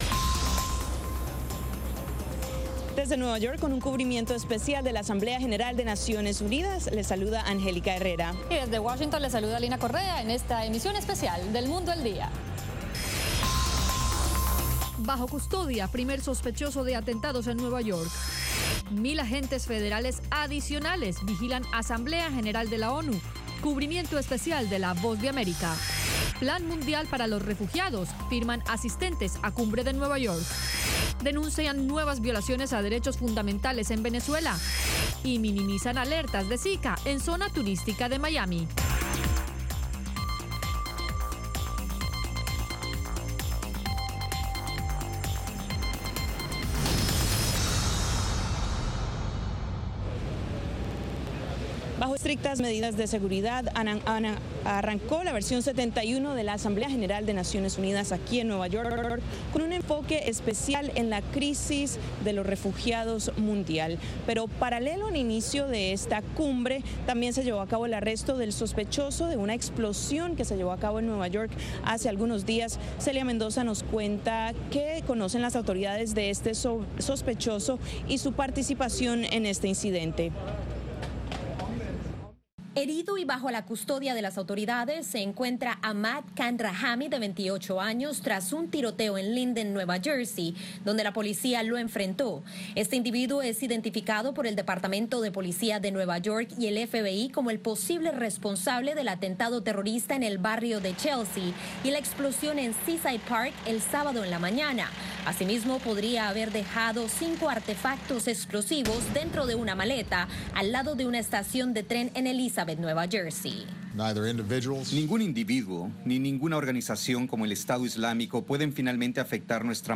Diez minutos de las noticias más relevantes del día, ocurridas en Estados Unidos y el resto del mundo.